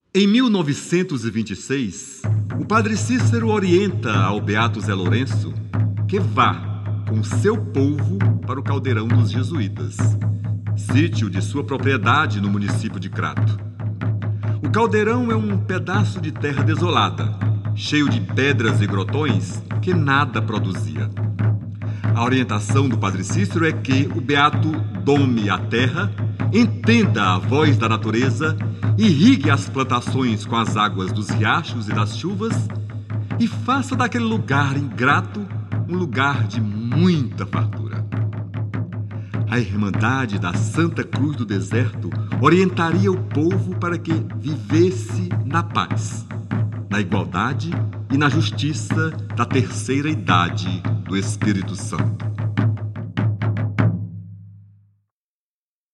Gênero: Regional